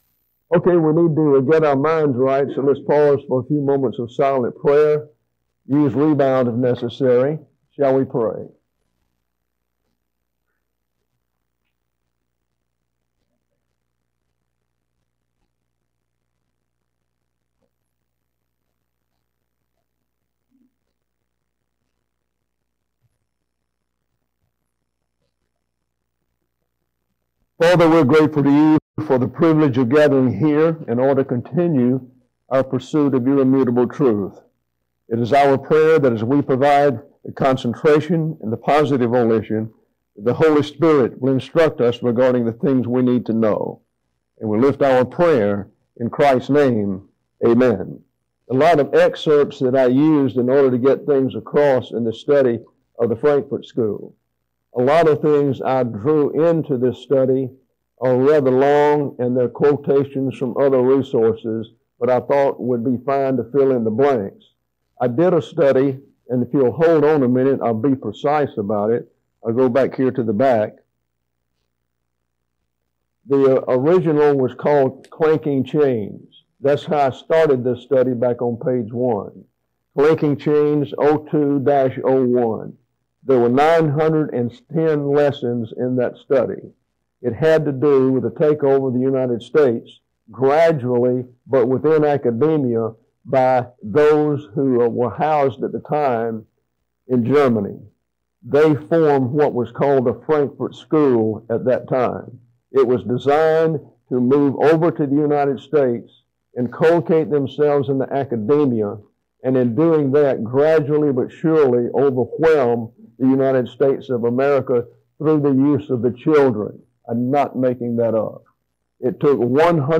2024 Shreveport Bible Conference: Session 5: